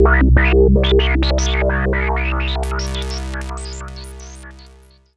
S AND H C2.wav